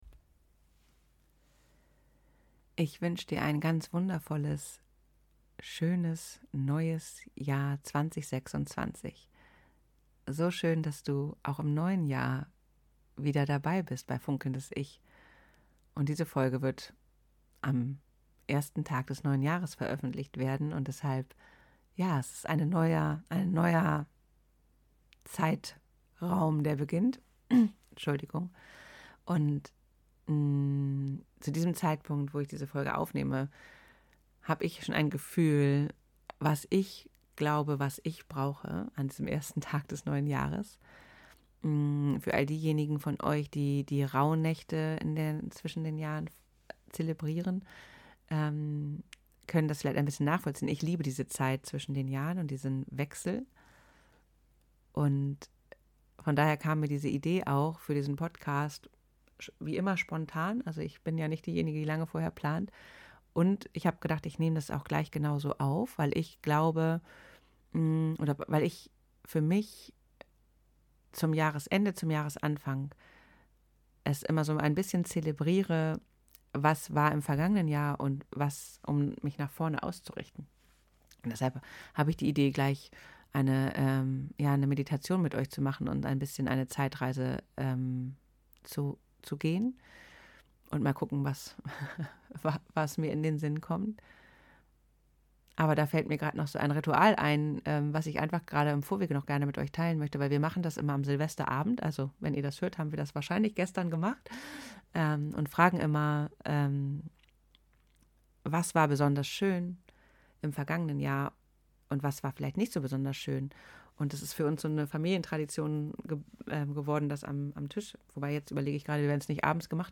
#27 - Herzlich willkommen 2026 - Meditation zum Jahresanfang ~ Funkelndes Ich Podcast